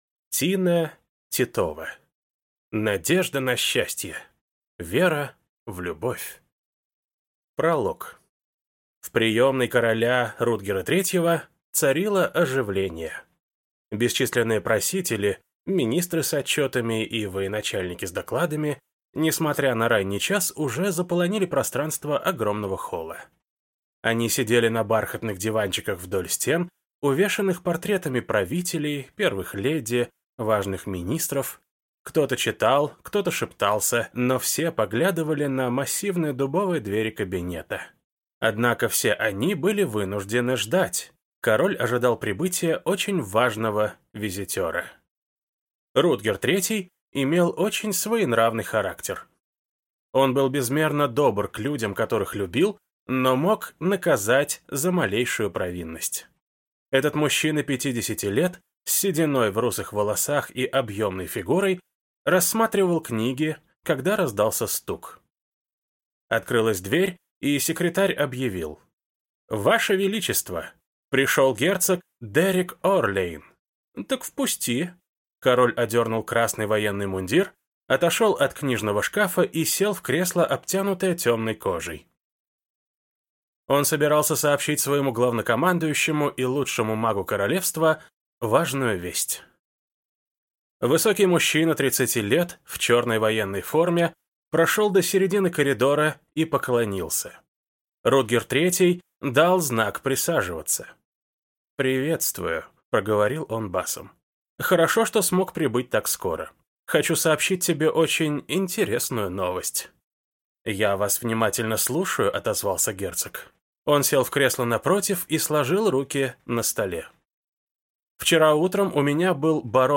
Аудиокнига Надежда на счастье. Вера в любовь | Библиотека аудиокниг